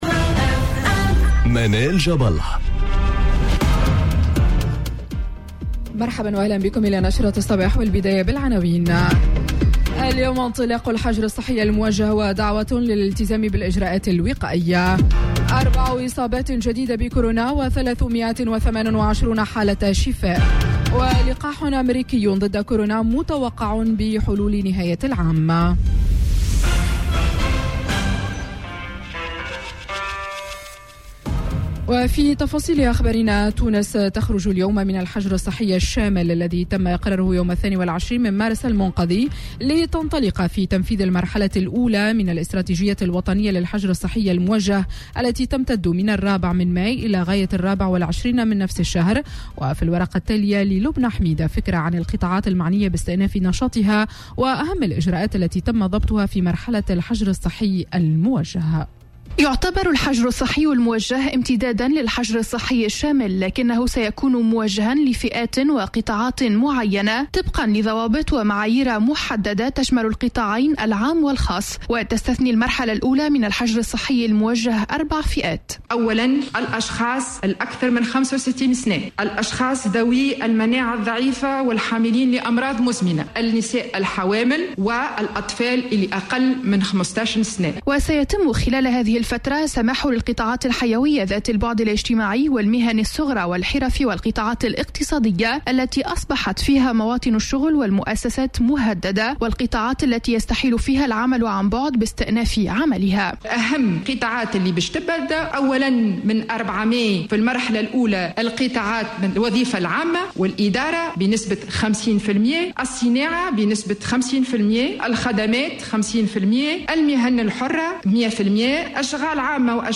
نشرة أخبار السابعة صباحا ليوم الإثنين 04 ماي 2020